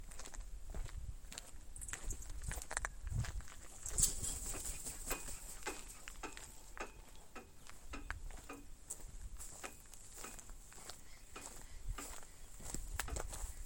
Jote Cabeza Negra (Coragyps atratus)
Nombre en inglés: Black Vulture
Condición: Silvestre
Certeza: Fotografiada, Vocalización Grabada